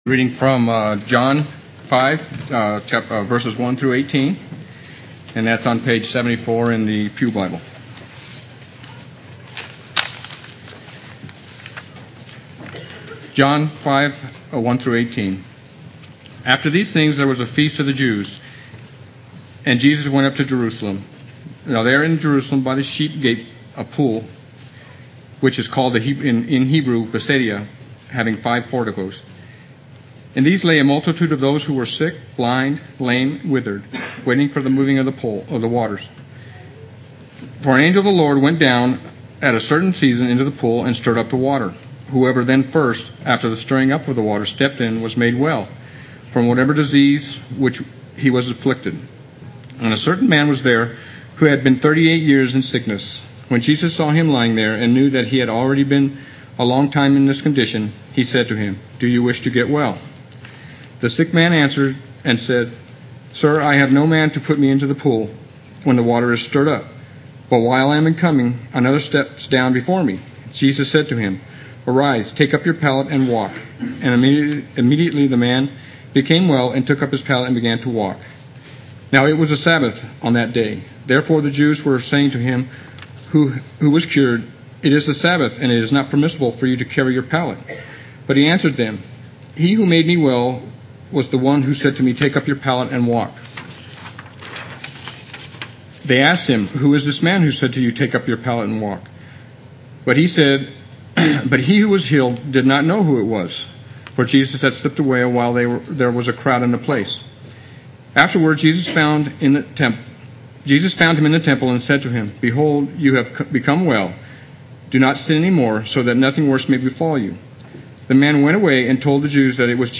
Play Sermon Get HCF Teaching Automatically.
and Walk Sunday Worship